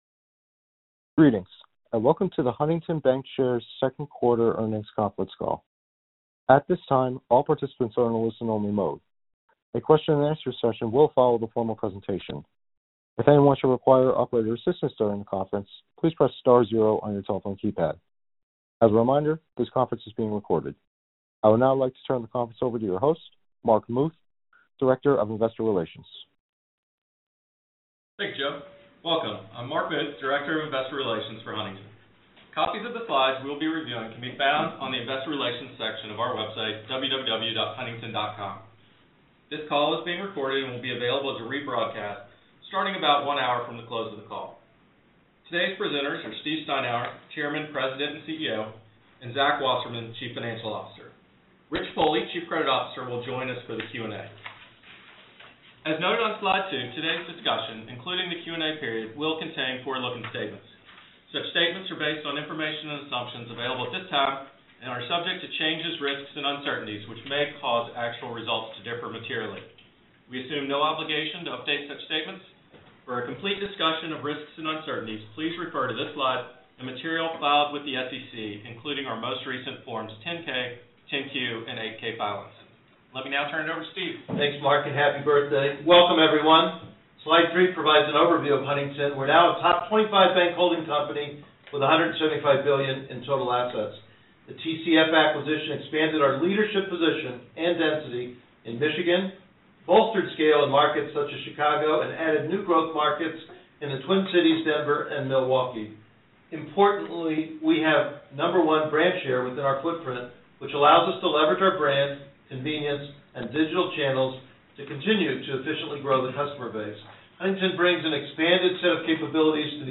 Huntington Bancshares 2021 Second Quarter Earnings Conference Call / Webcast